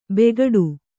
♪ begaḍu